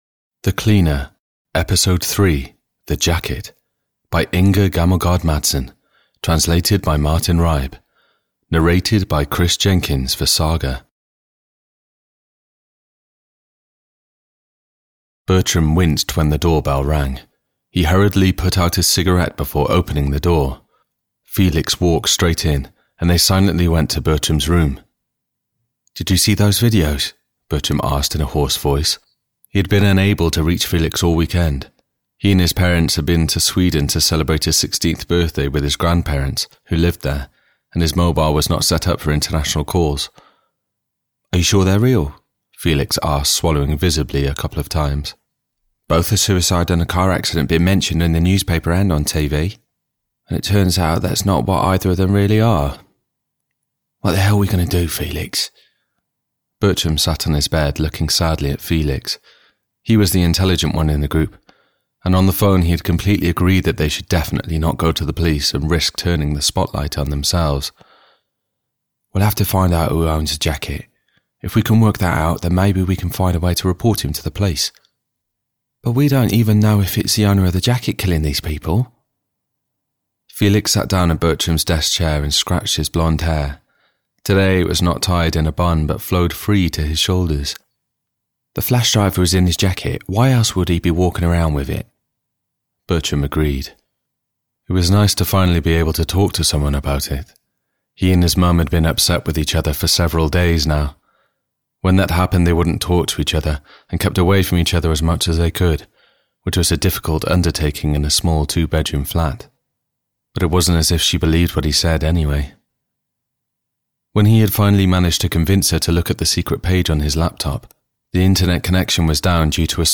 The Cleaner 3: The Jacket (EN) audiokniha
Ukázka z knihy